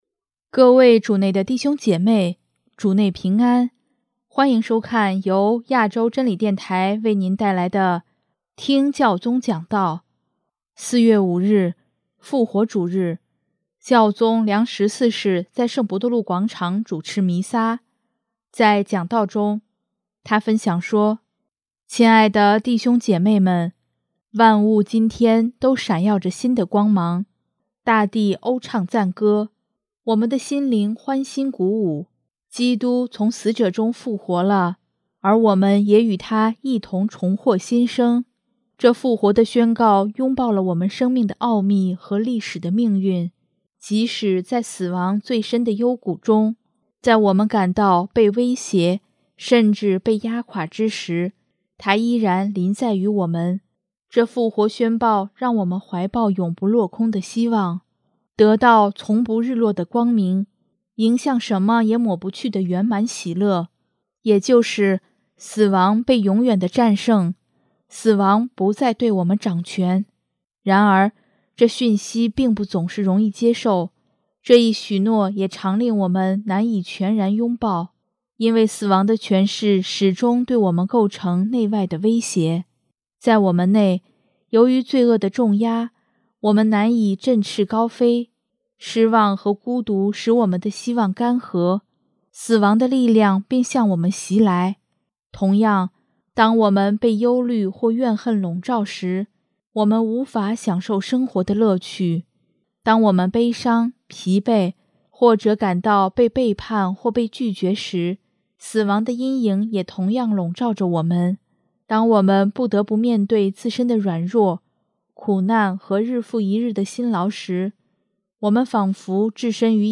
4月5日，复活主日，教宗良十四世在圣伯多禄广场主持弥撒，在讲道中，他分享说：